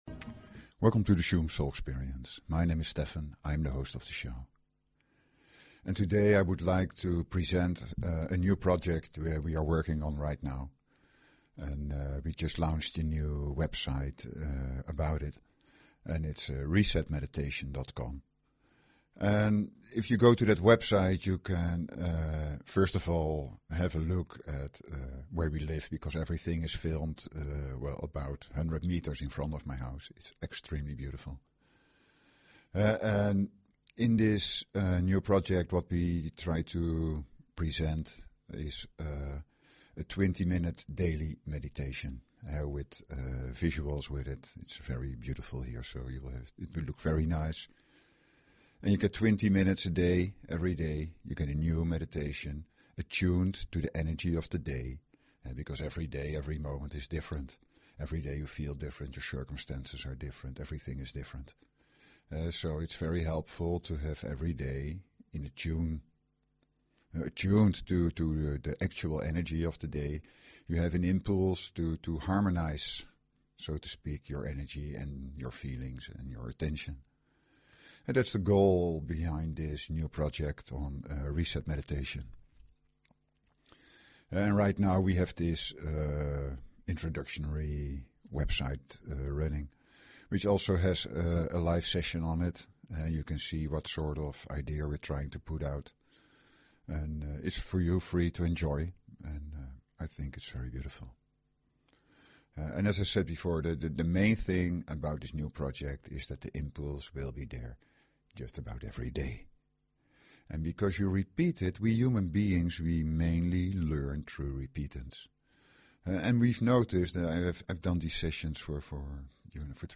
Talk Show Episode, Audio Podcast, Shuem_Soul_Experience and Courtesy of BBS Radio on , show guests , about , categorized as
Listening through headphones - especially during the healing - makes it even easier to share in the experience.